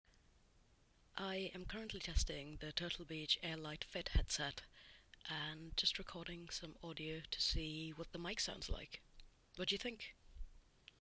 The Airlite Fit sports a flip-up mic on the left earcup that mutes automatically when raised.
Here’s how it sounds with no background noise:
Mic sample 1
You can hear my voice loud, clear, and every word distinct.